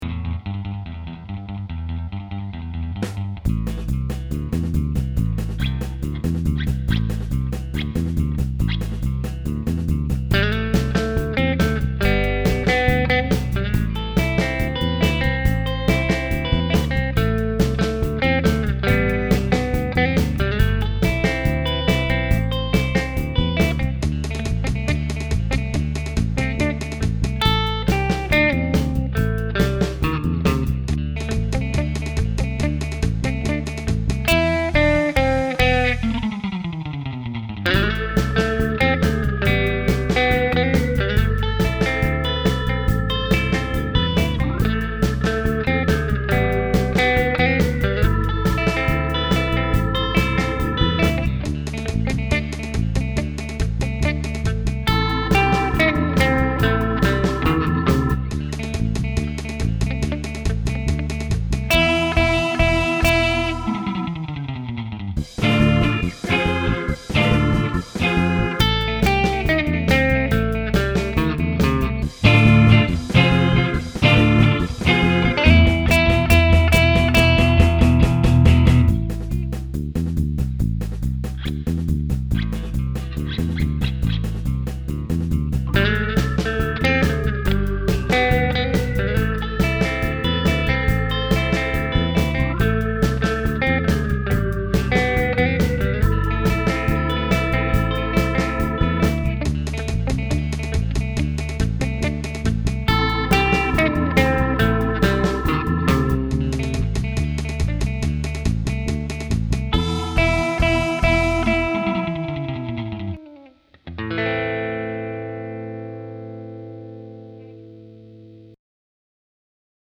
きらめくギター、ベース、アジなキーボード